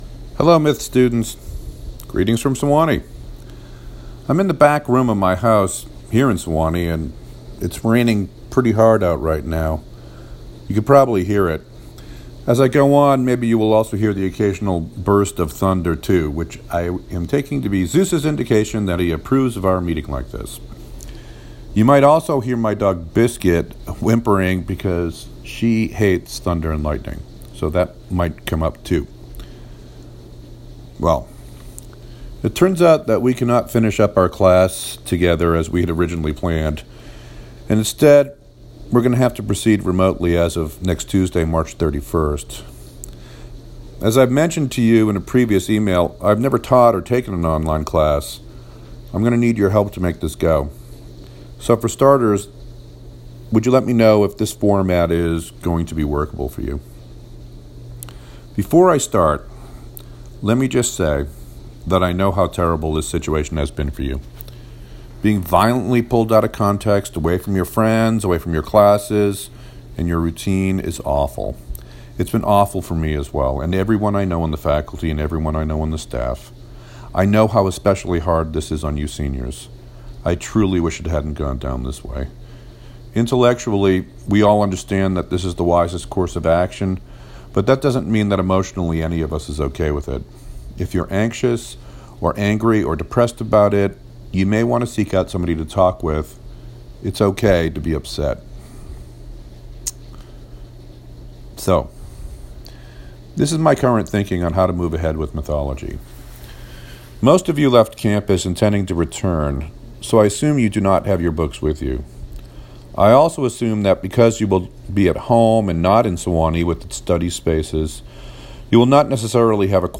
Let me ask you to listen to this voice memo above, in .m4a format, and tell me whether or not listening to a recording like this is going to be OK for you as a way for me to run class.